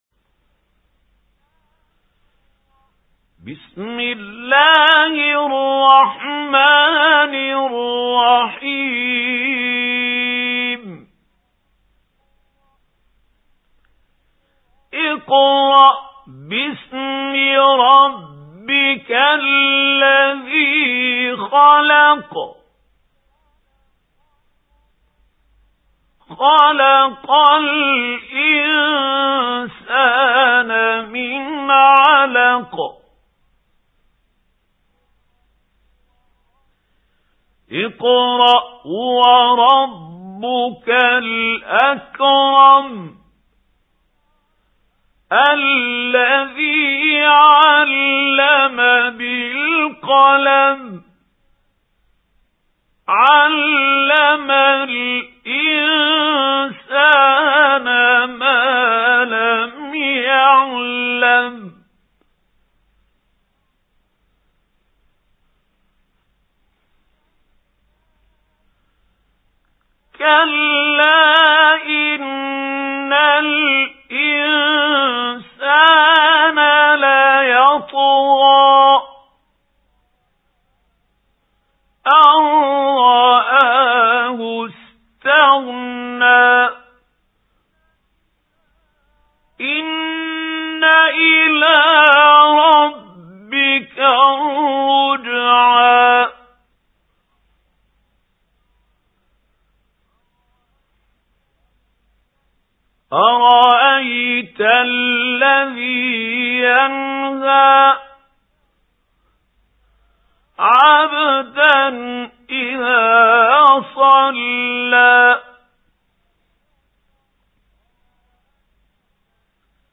سُورَةُ العَلَقِ بصوت الشيخ محمود خليل الحصري